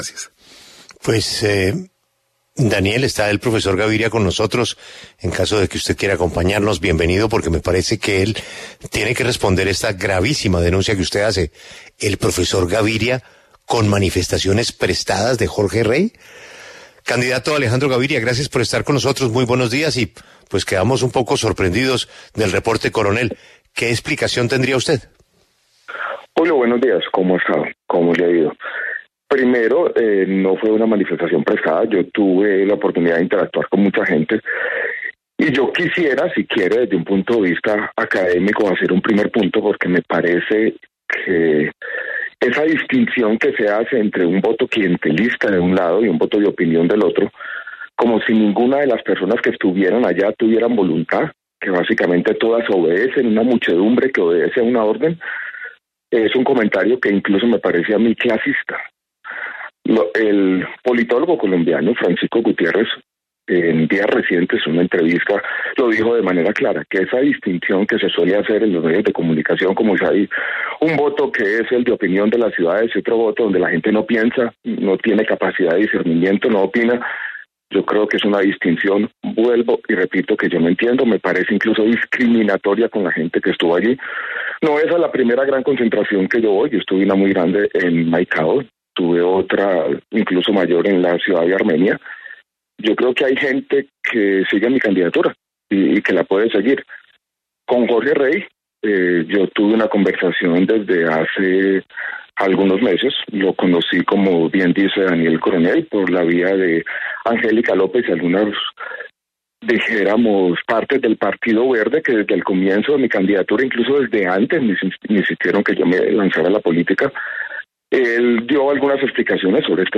El precandidato presidencial Alejandro Gaviria aseguró en La W que hay hipocresía en la Coalición Centro Esperanza.